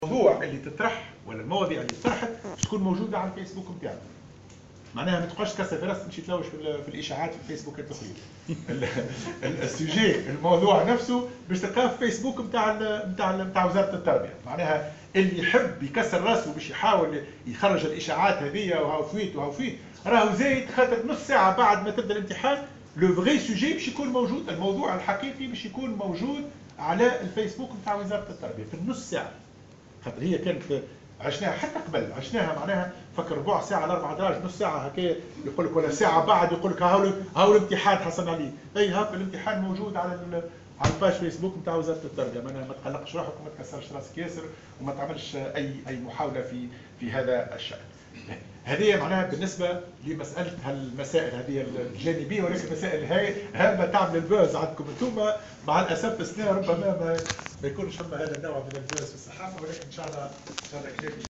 Le ministre de l'Education, Hatem Ben Salem a annonc�, dans une d�claration accord�e � Jawhara FM ce lundi 4 juin 2018, que les sujets des examens de l'�preuve du baccalaur�at seront publi�s sur la page Facebook officielle du d�partement et ce une demie heure apr�s le d�but des examens..